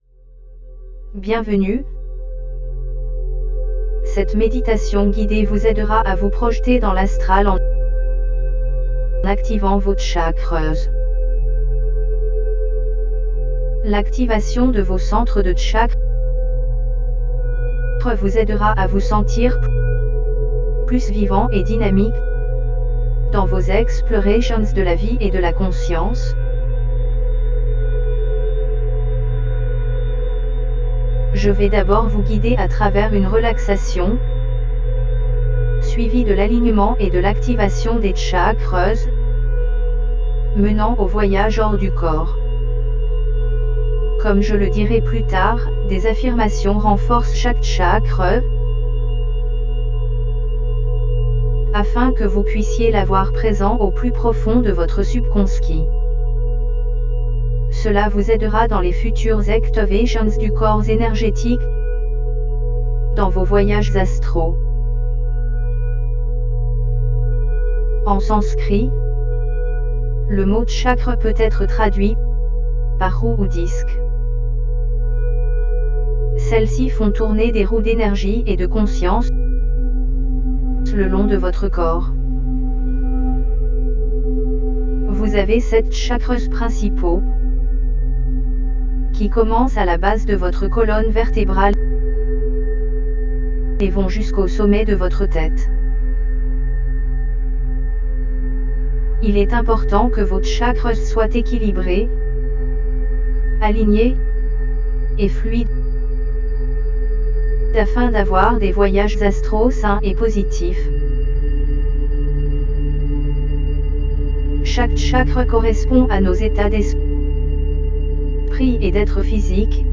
Bienvenue dans cette méditation guidée par projection astrale où vous serez guidé à travers la technique d'activation des chakras.
Cette technique se concentre sur l'activation de sept de vos chakras principaux et la création d'un double astral, ou véhicule, puis le transfert de votre conscience vers ce véhicule. Nous vous recommandons d'utiliser des écouteurs / bouchons d'oreilles pour une expérience optimale car la méditation est intégrée avec des battements binauraux delta de 3 Hz.
OBEGuidedAstralProjectionChakraMeditationHypnosisFR.mp3